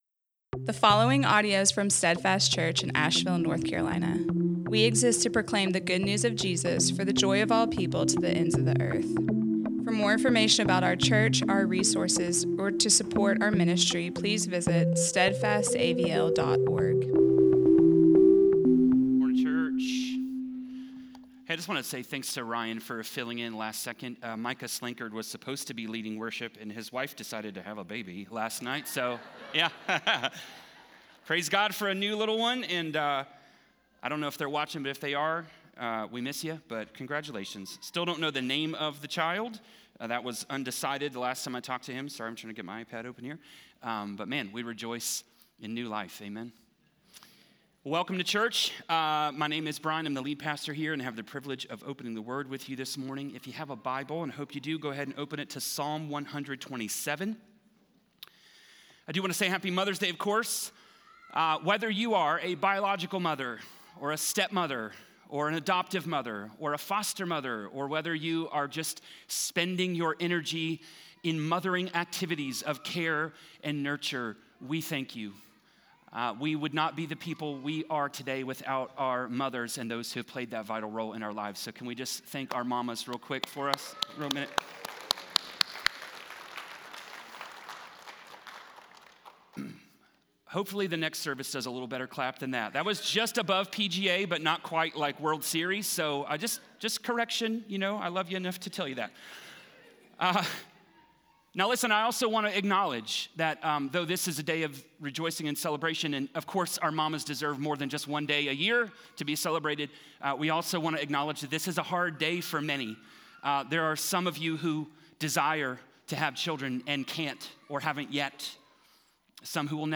Steadfast Church Sermons